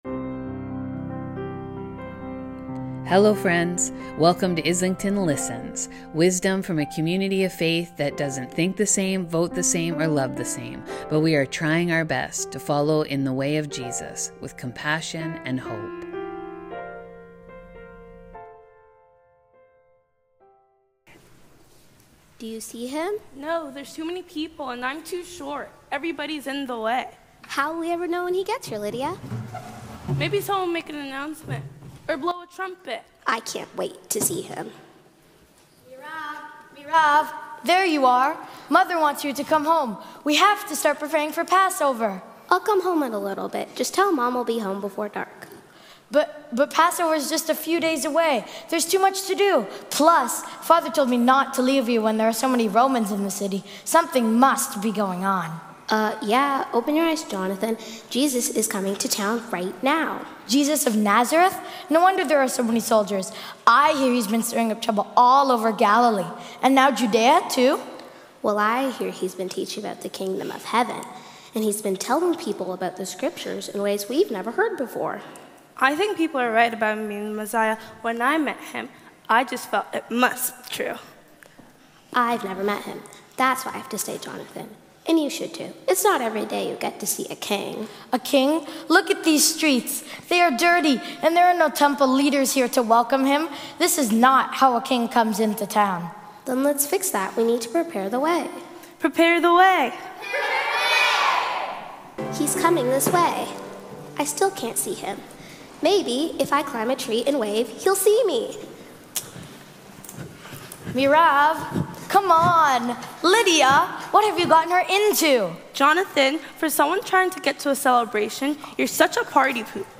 On Sunday, March 29, Our children led us in retelling the palm Sunday story.